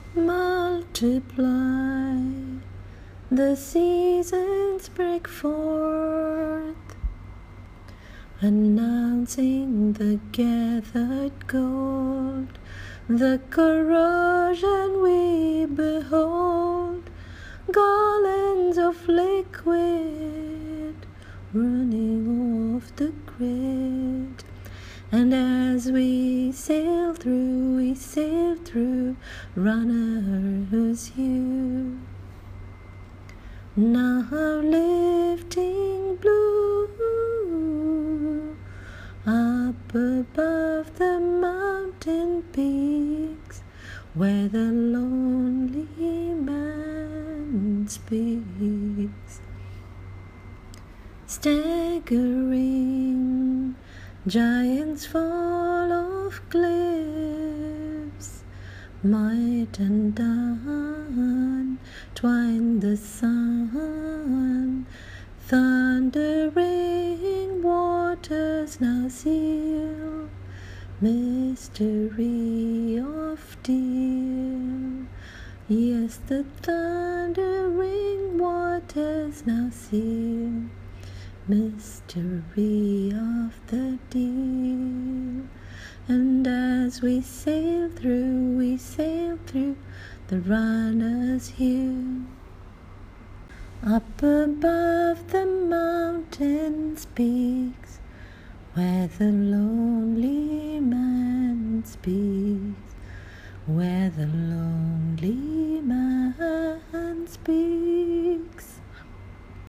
Singing of the poem with modified lyrics:
twined-the-sun-sung.m4a